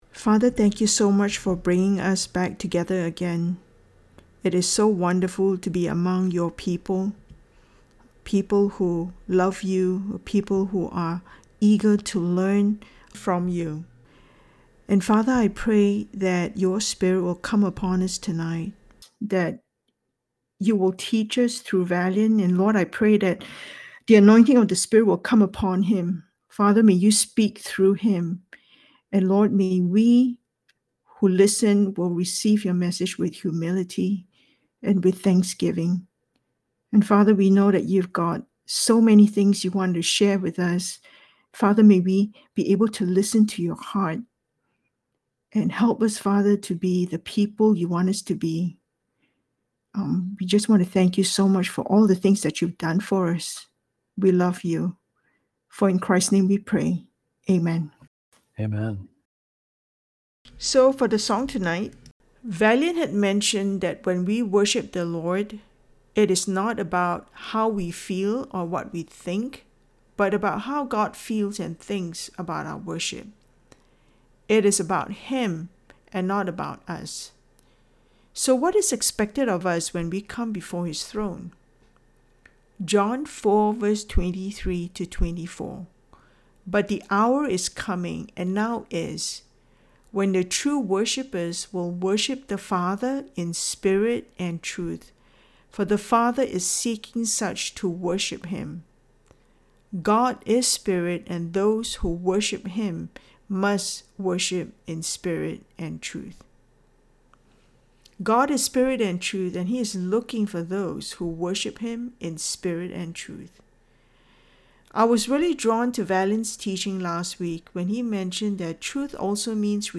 A message from the series "The Spirit and the Word."